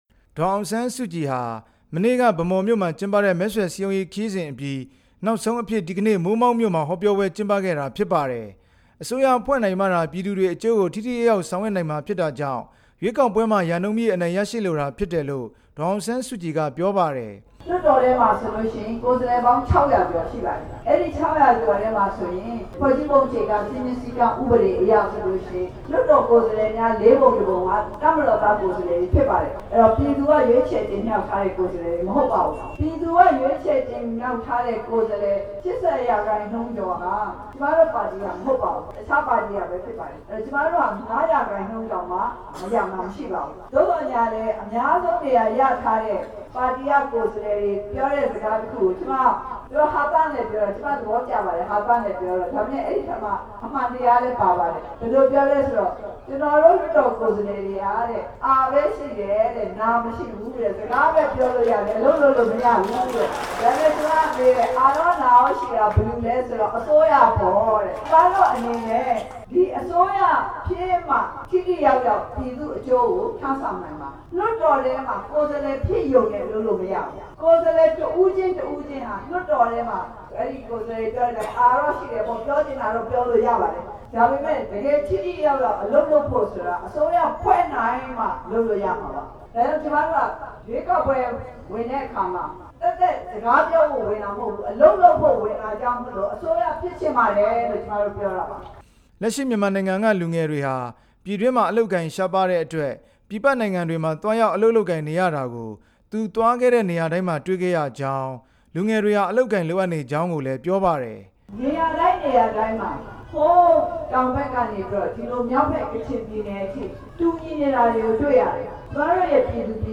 အမျိုးသားဒီမိုကရေစီအဖွဲ့ချုပ် ဥက္ကဌ ဒေါ်အောင်ဆန်းစုကြည်ဟာ ကချင်ပြည်နယ် မိုးမောက်မြို့နယ် ခါနန်ရပ်ကွက်က လောကမာရဇိန်ဘုရားဝင်းမှာ ဒီနေ့ မဲဆွယ်စည်းရုံး ရေးအတွက် ဟောပြောခဲ့ပါတယ်။